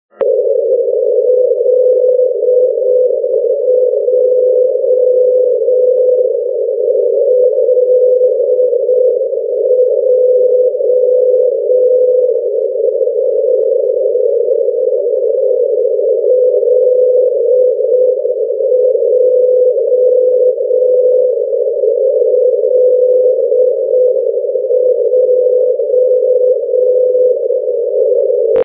106 Кб 20.04.2011 19:50 Громово NDB morze